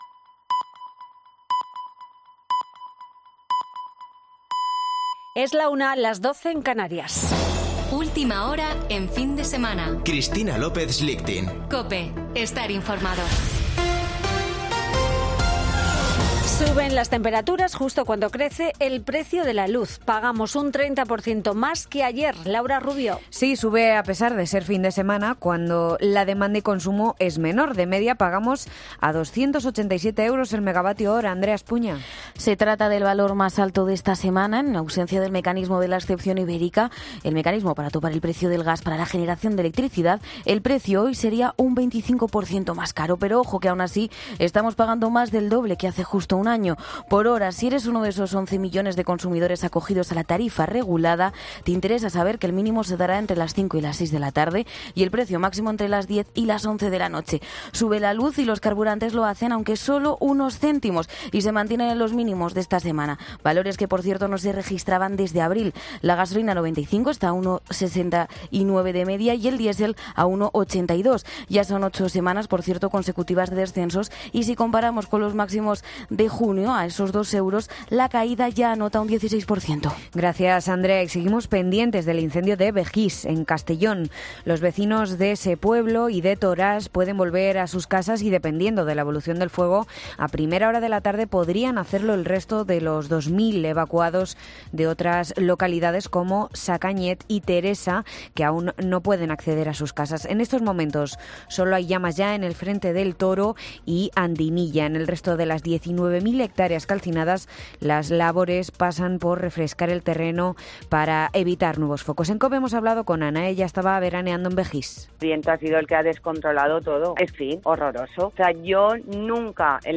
Boletín de noticias de COPE del 20 de agosto de 2022 a la 13.00 horas